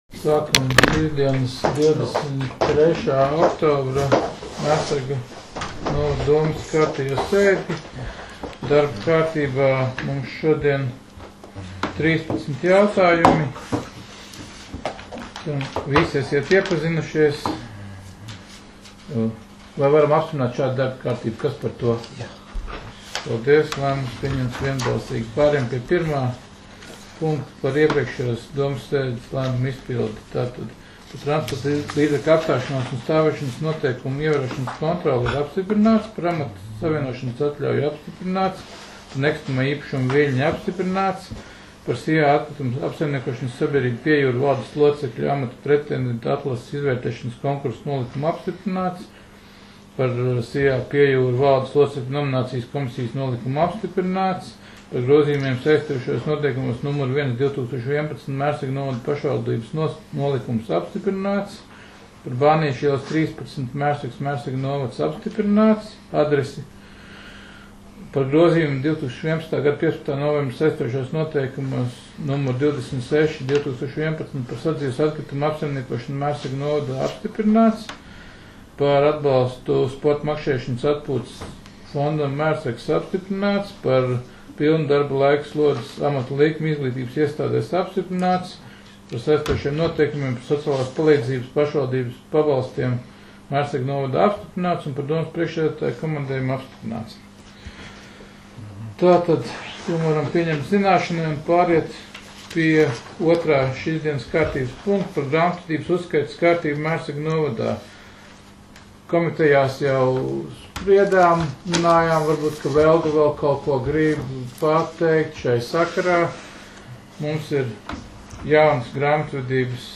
Mērsraga novada domes sēde 23.10.2019.